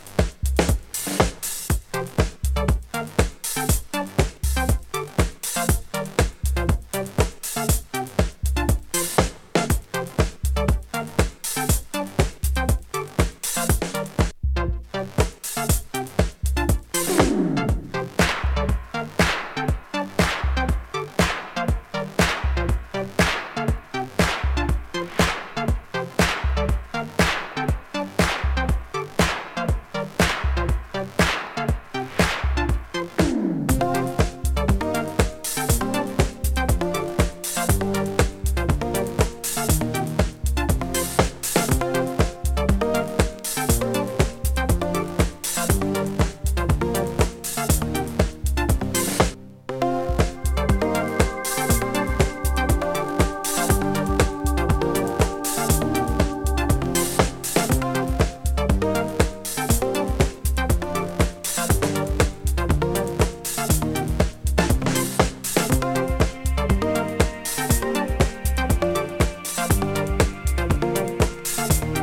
Nu-Discoタイプのビートに、切ないシンセフレーズがGood！